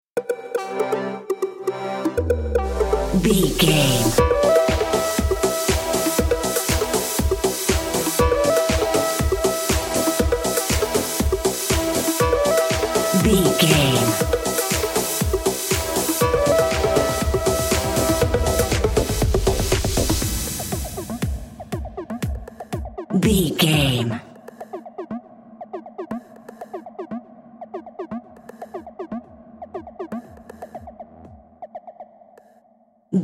Aeolian/Minor
groovy
dreamy
smooth
futuristic
driving
drum machine
synthesiser
house
techno
trance
instrumentals
synth leads
synth bass
upbeat